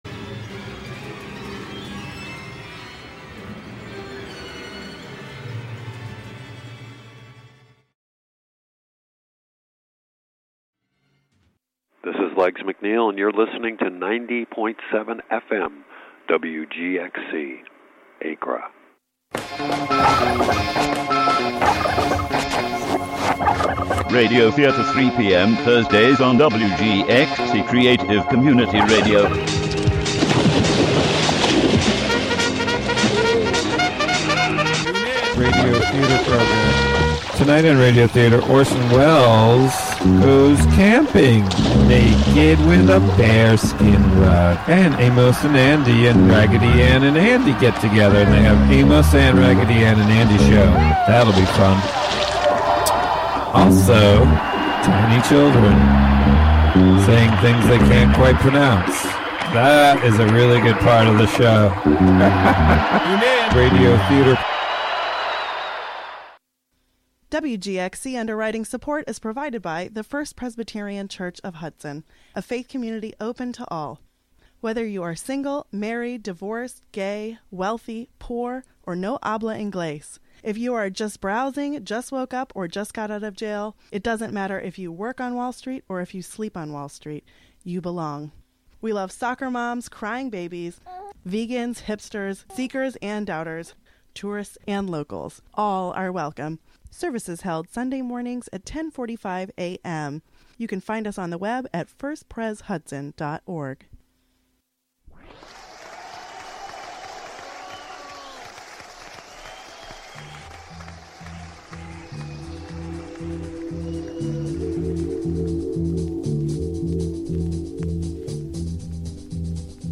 An interview with cellist